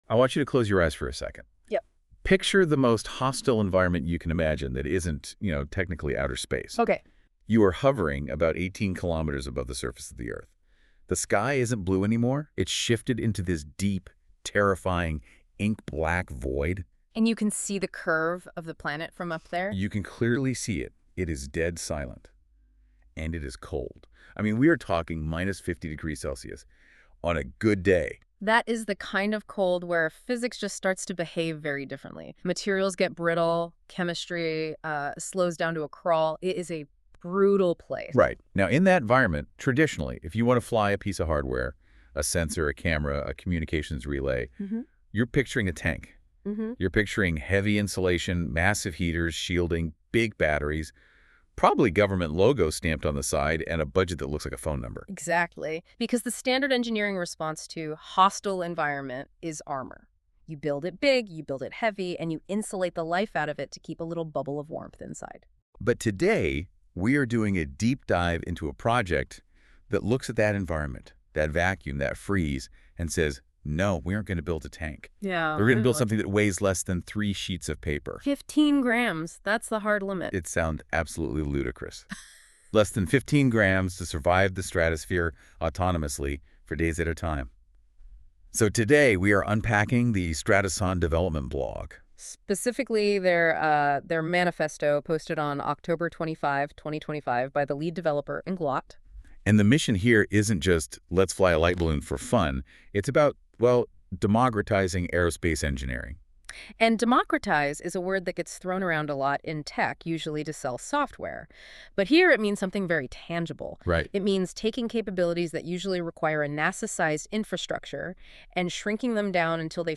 🎧 Listen to this article: NotebookLM Podcast An AI-generated audio discussion created by Google’s NotebookLM